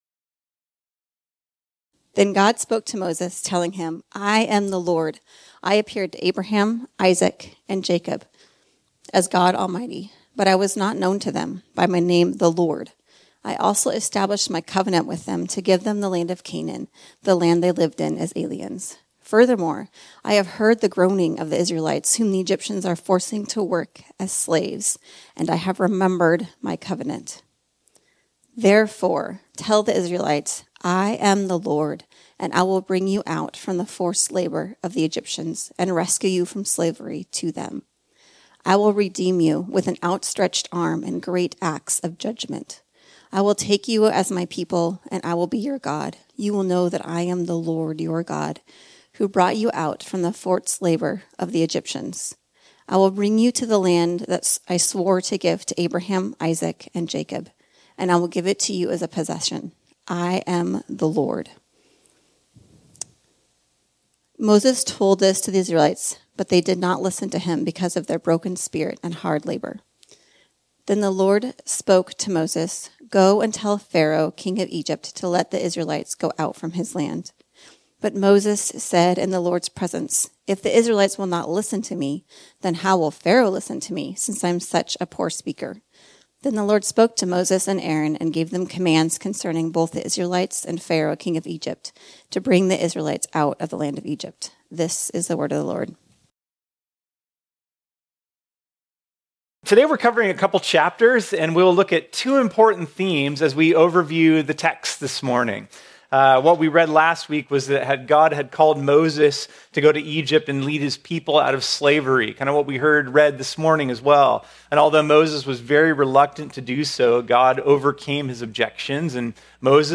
This sermon was originally preached on Sunday, June 11, 2023.